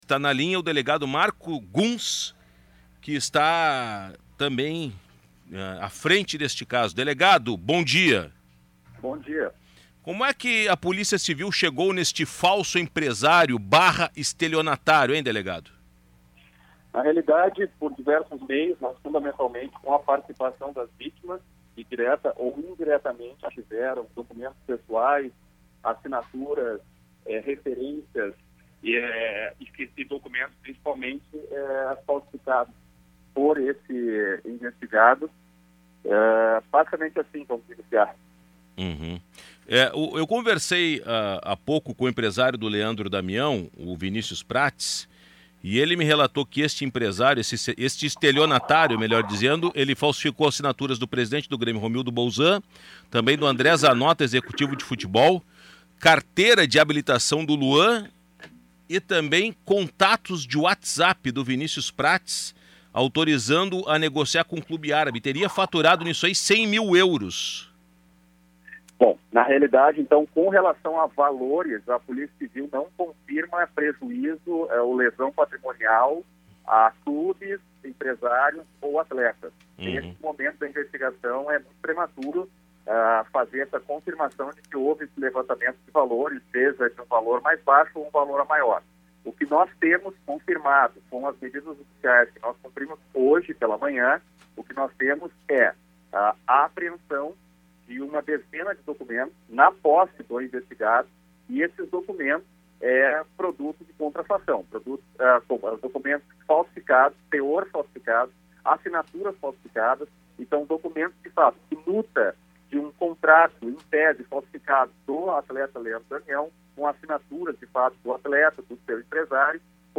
ENTREVISTA-DELEGADO-d1810.mp3